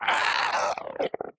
Minecraft Version Minecraft Version snapshot Latest Release | Latest Snapshot snapshot / assets / minecraft / sounds / mob / strider / death1.ogg Compare With Compare With Latest Release | Latest Snapshot